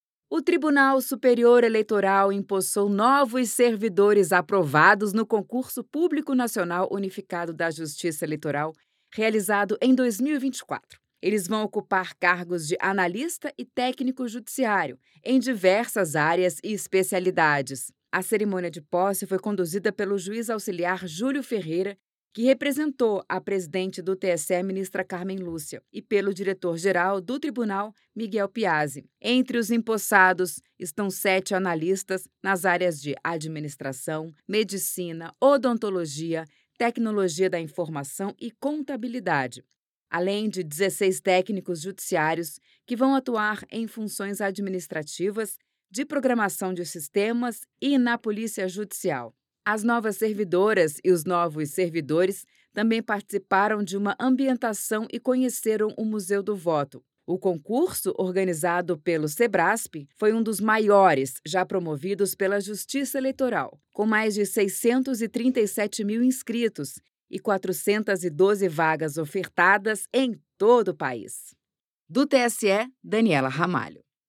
Durante a cerimônia, autoridades destacaram a importância do trabalho dos servidores para o fortalecimento da democracia e para os desafios da realização das eleições no país.